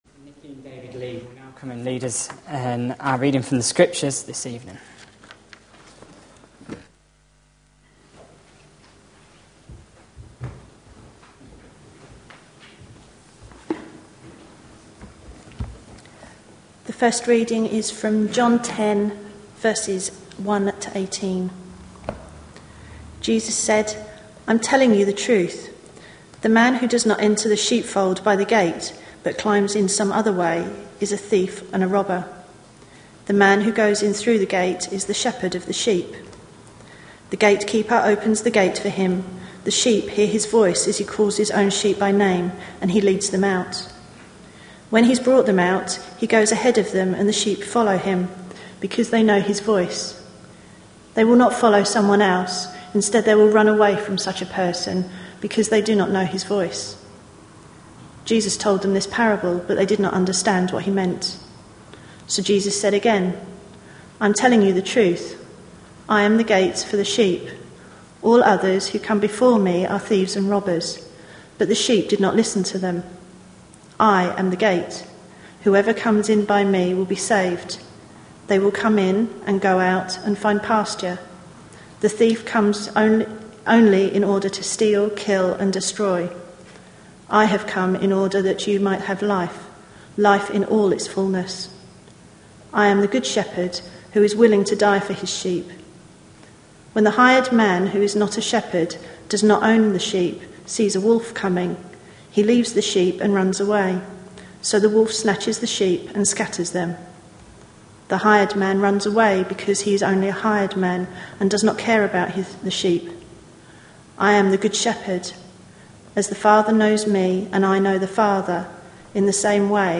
A sermon preached on 25th March, 2012, as part of our Looking For Love (6pm Series) series.